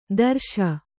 ♪ darśa